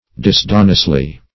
disdainously - definition of disdainously - synonyms, pronunciation, spelling from Free Dictionary Search Result for " disdainously" : The Collaborative International Dictionary of English v.0.48: Disdainously \Dis*dain"ous*ly\, adv.